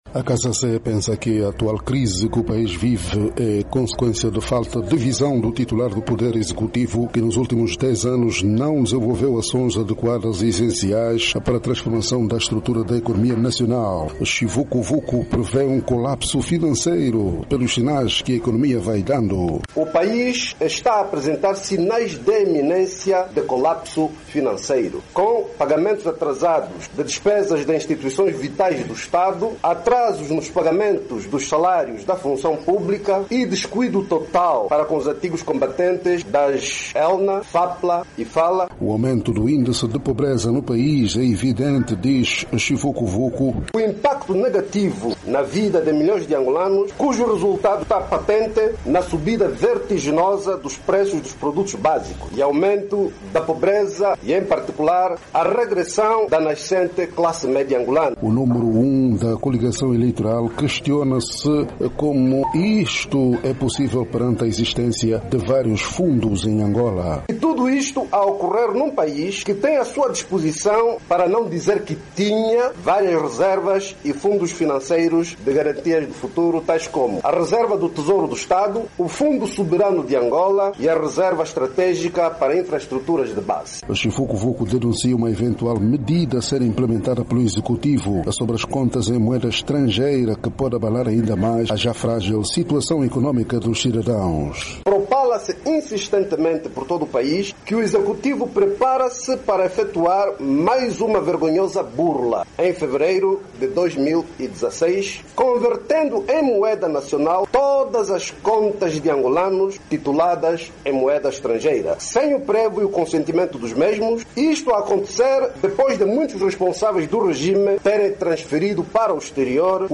Em conferência de imprensa, Chivukuvuku responsabilizou a falta de visão de José Eduardo dos Santos que, nos últimos 10 anos, não desenvolveu acções adequadas e essenciais para transformação da estrutura da economia nacional.